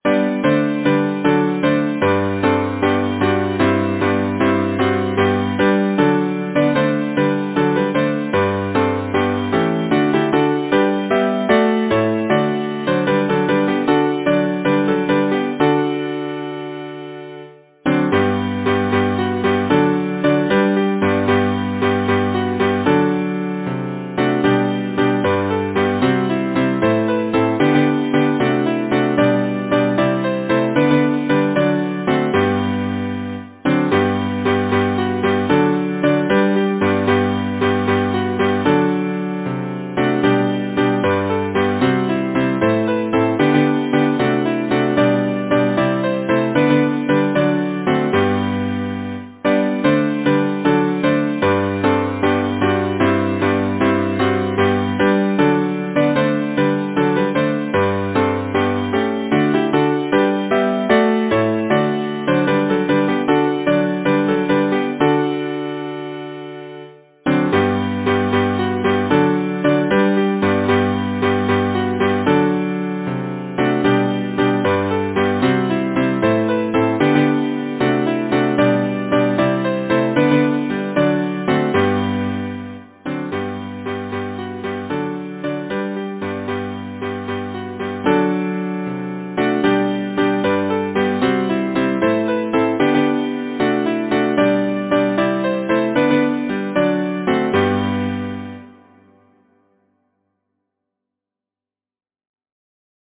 Title: The Muleteer Composer: Augustus Meves Lyricist: Number of voices: 4vv Voicing: SATB Genre: Secular, Partsong
Language: English Instruments: A cappella